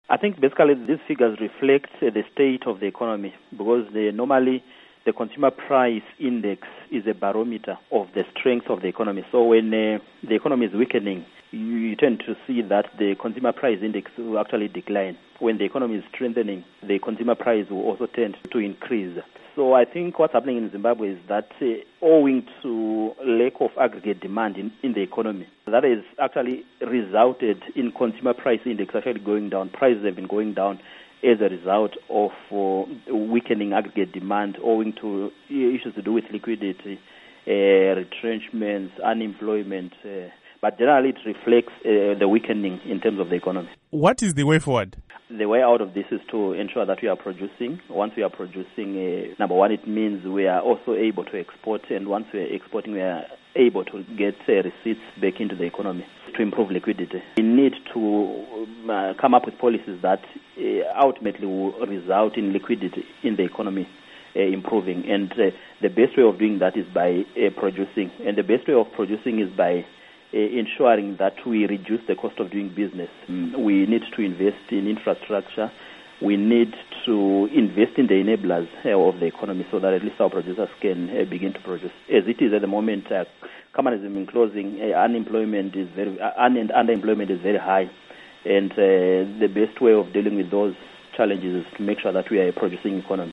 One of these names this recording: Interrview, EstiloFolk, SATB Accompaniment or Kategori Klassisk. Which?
Interrview